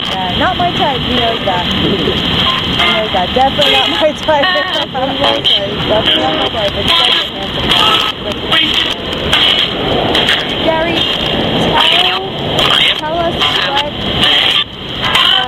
At 5 seconds you hear a male voice respond..."Ouch!".
At 11 seconds,  you hear a voice say...."Radio....play it.....damn it".